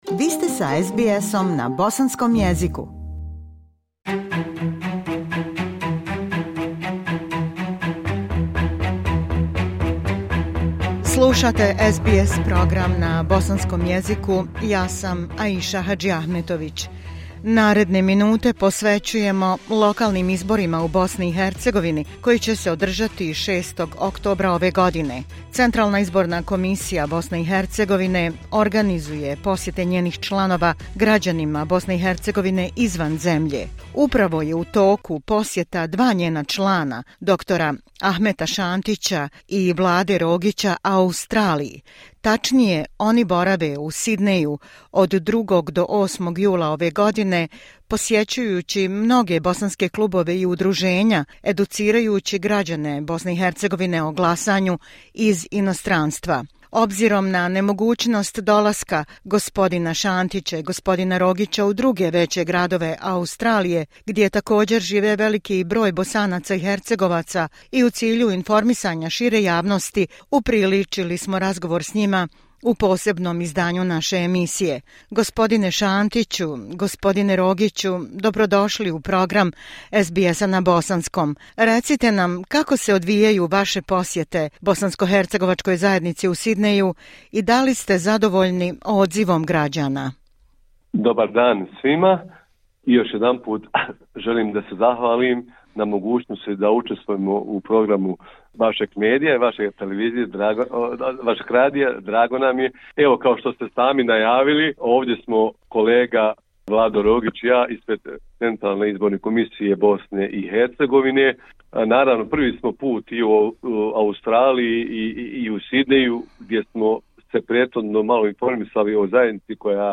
Tačnije, oni borave u Sydneyu od 2. do 8. jula ove godine posjećujući mnoge bosanske klubove i udruženja educirajući građane BiH o glasanju iz inostranstva. Obzirom na nemogućnost dolaska gospodina Šantića i Rogića u druge veće gradove Australije, gdje također živi veliki broj Bosanaca i Hercegovaca, i u cilju informisanja šire javnosti, upriličili smo razgovor s njima u posebnom izdanju naše emisije.